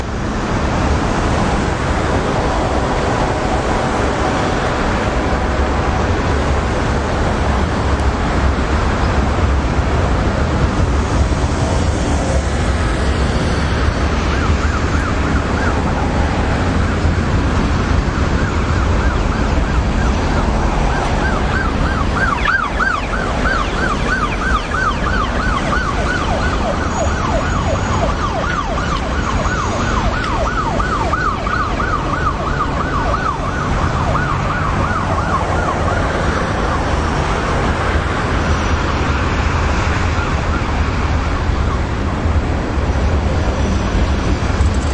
警报器
描述：救护车警报器在纽约市交通中闪烁.Tascam DR07便携式立体声录音机。
标签： 救护车 纽约 光点 警笛
声道立体声